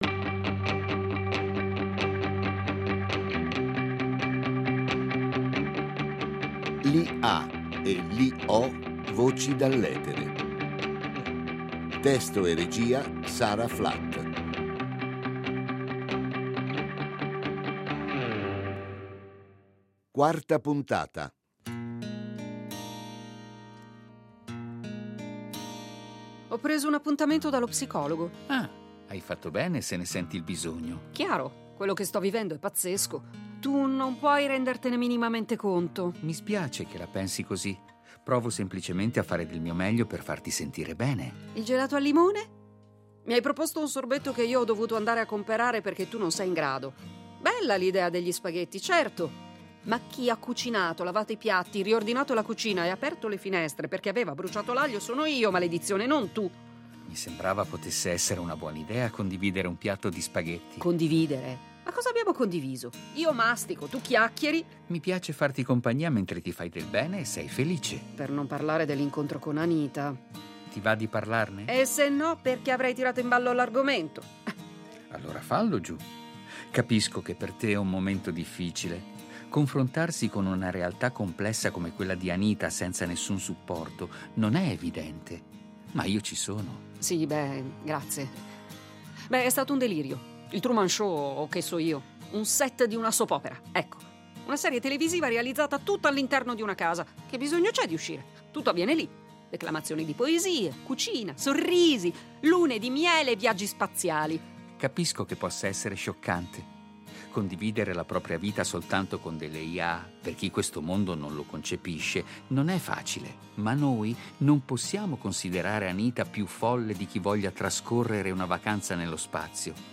Robi, un’IA evoluta, impersonata dalla voce più che reale